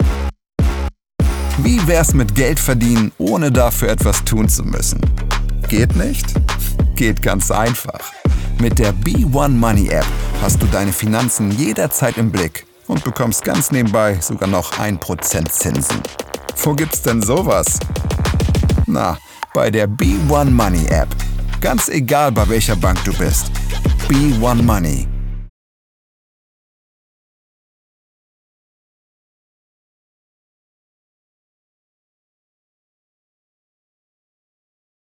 dunkel, sonor, souverän, plakativ
Mittel plus (35-65)
Werbung 02 - werblich
Commercial (Werbung)